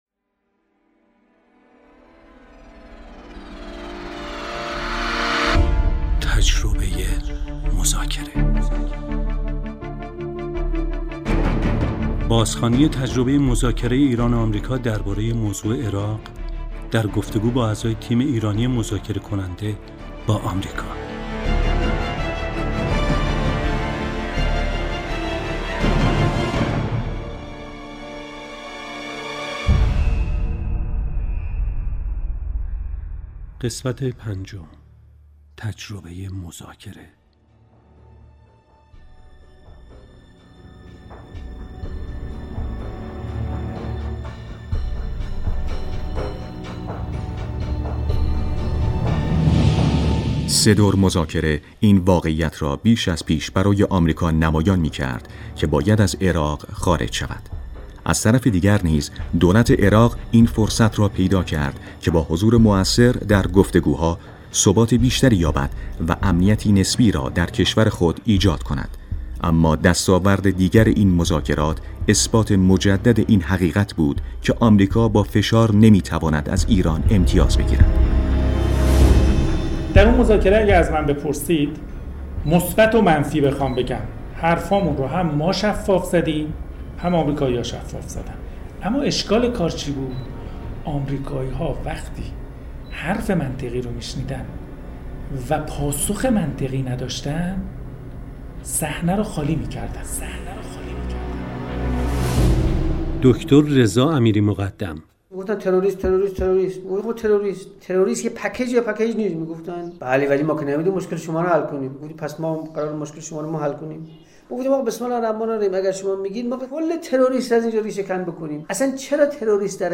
مستند صوتی تجربه مذاکره:تجربه مذاکره قسمت 5 (آخر)